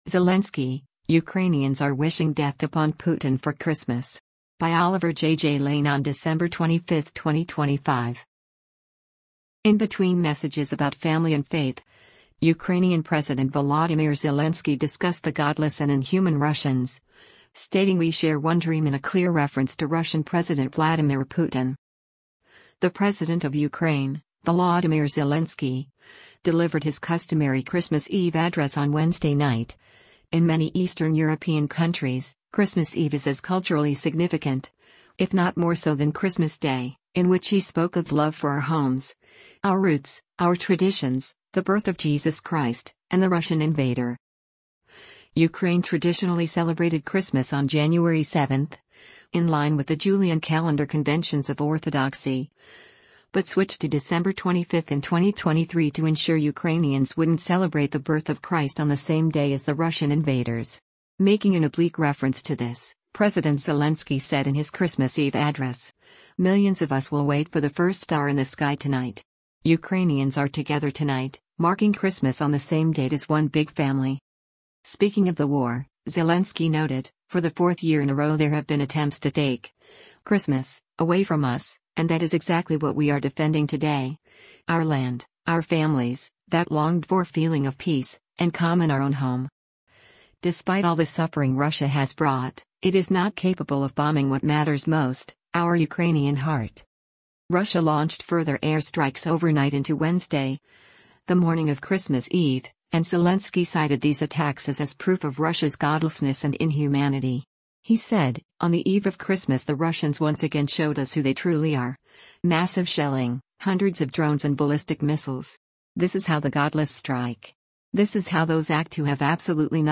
The President of Ukraine, Volodymyr Zelensky, delivered his customary Christmas Eve address on Wednesday night — in many Eastern European countries, Christmas Eve is as culturally significant, if not more so than Christmas day — in which he spoke of “love for our homes, our roots, our traditions”, the birth of Jesus Christ, and the Russian invader.